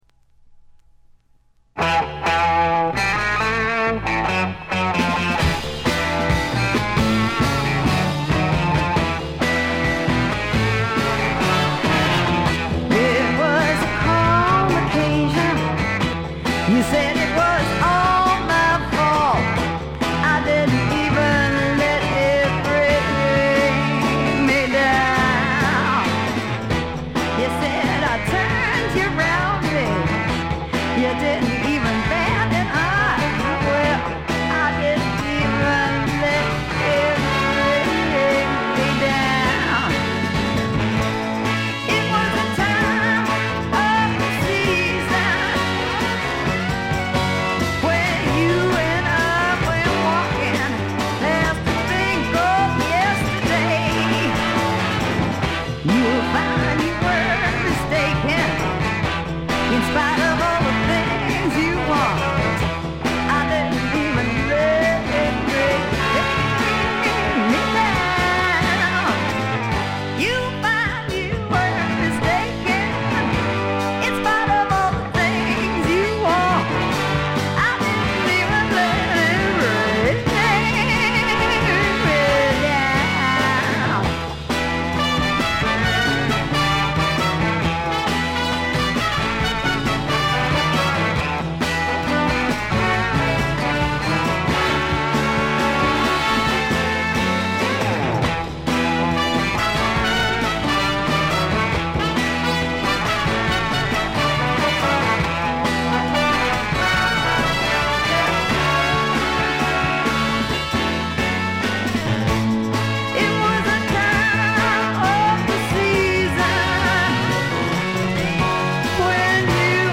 散発的なプツ音少し。
スワンプ、R&B、ジャズ、ブルース、サイケのごった煮で
ホーン・セクションを含む大世帯のバンドを狭いライヴ酒場で聴いているような強烈な圧力があります。
試聴曲は現品からの取り込み音源です。
Vocals